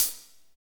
HAT P B C05R.wav